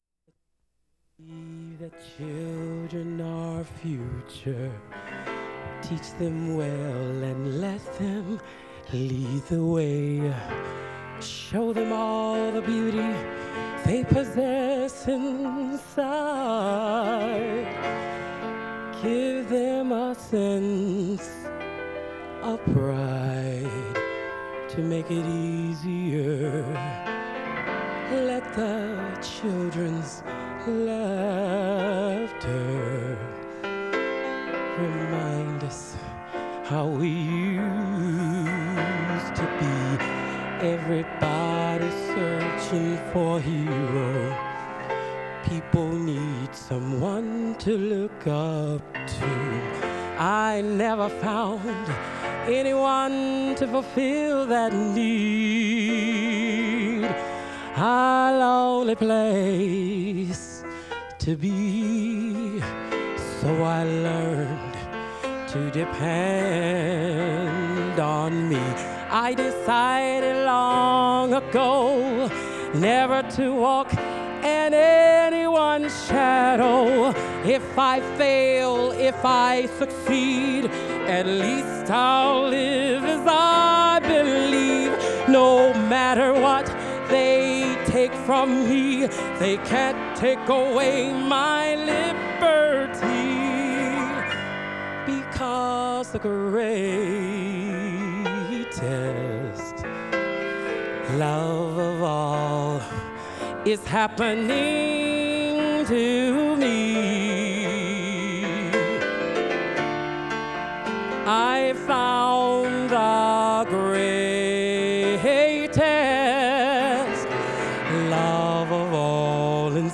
Genre: Popular / Standards | Type: Solo